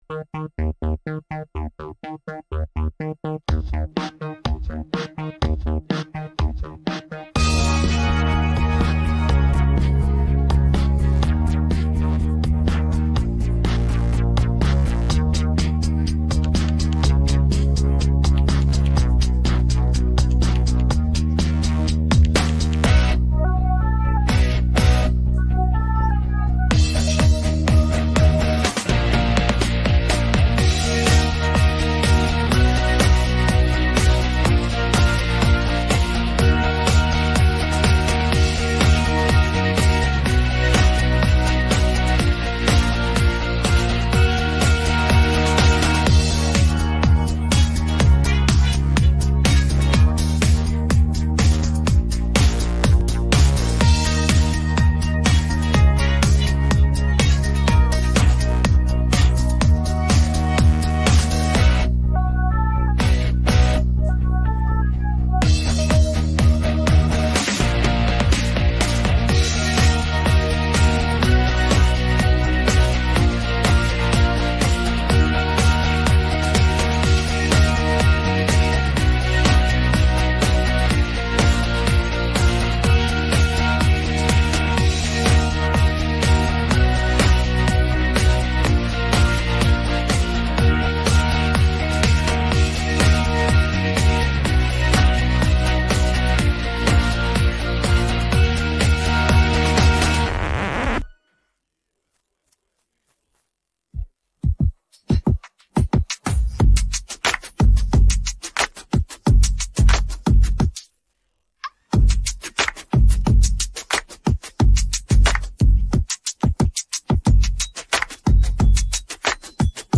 rock караоке 39